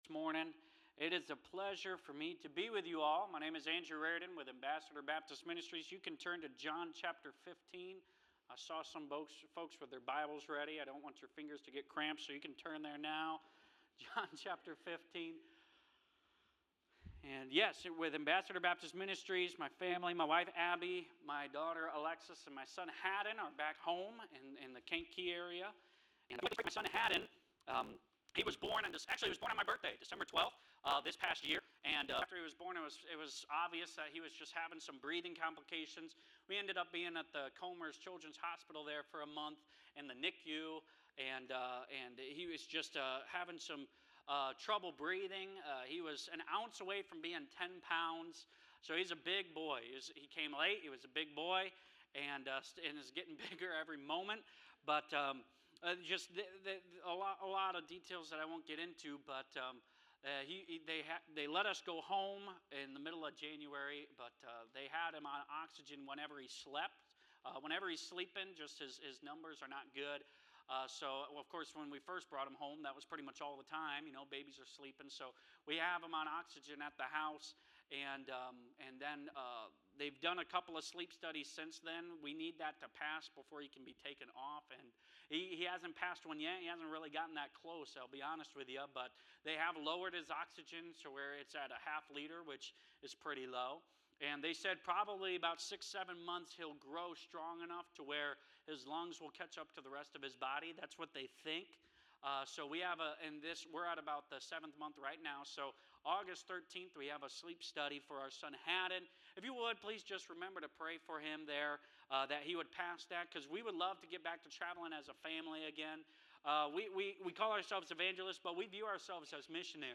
Preaching from the Pulpit | First Baptist Church